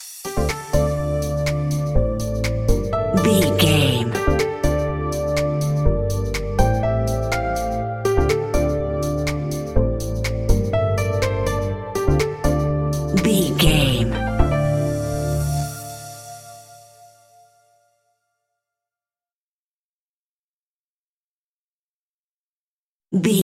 Aeolian/Minor
groovy
uplifting
driving
energetic
repetitive
synthesiser
drum machine
electric piano
instrumentals
synth leads
synth bass